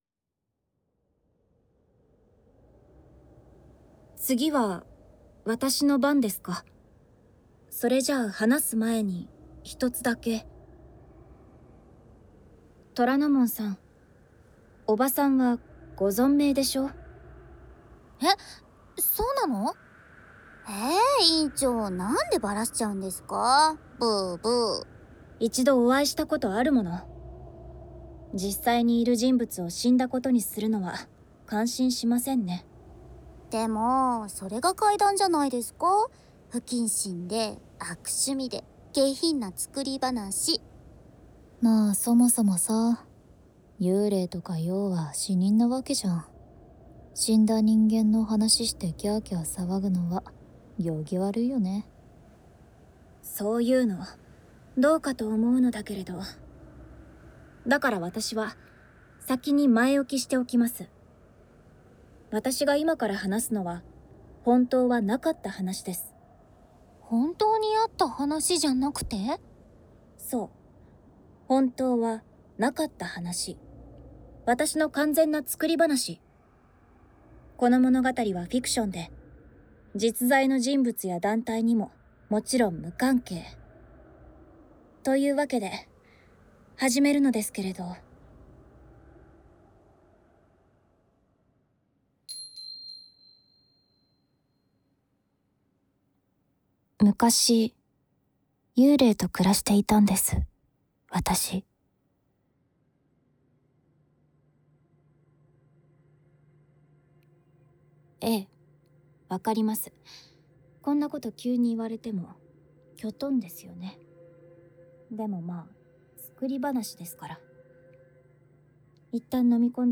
【简体中文版】【动画化音声】真的很恐怖的百物语ASMR。